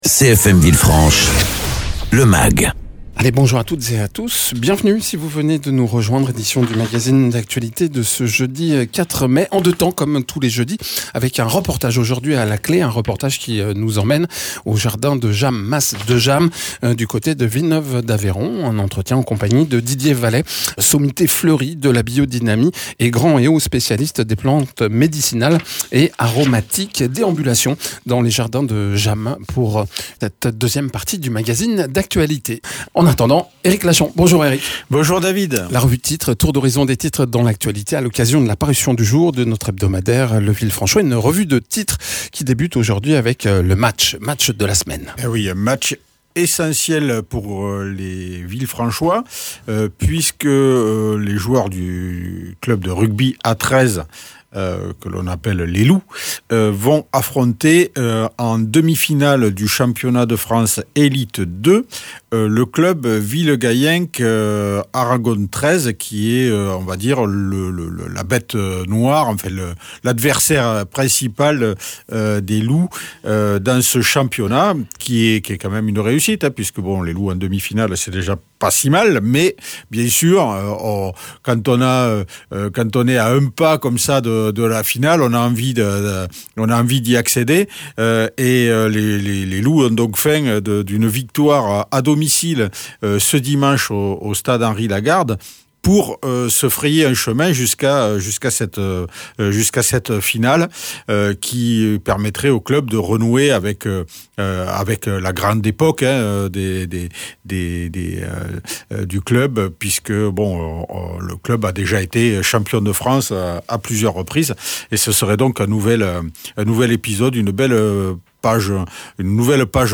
Avec, le traitement des déchets robotisé en fin d’année, la prise de fonction du nouveau sous-préfet, une opération de dépollution, le championnat de France de Rugby à XIII Elite 2 etc. Egalement dans ce mag, reportage consacré aux Jardins de Jammes.